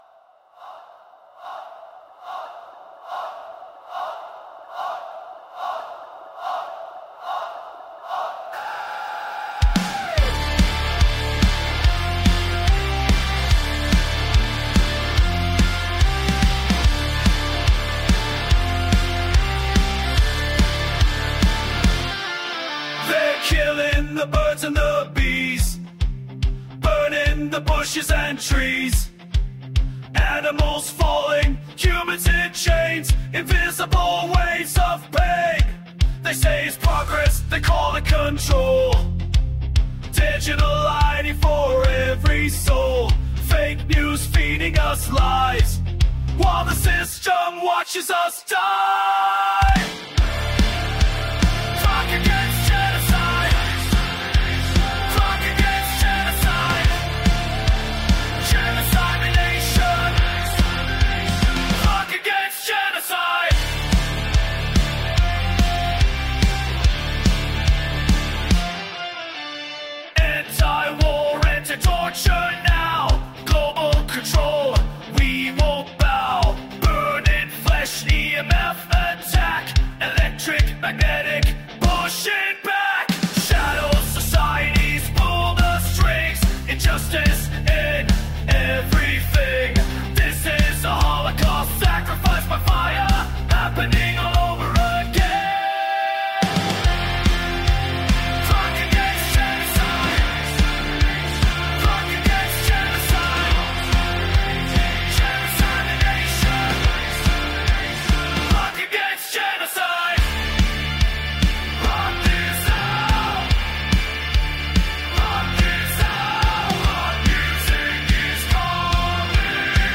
Heavy Rock Anthem VIDEO! WARNING PROTEST ANTI-GENOCIDE!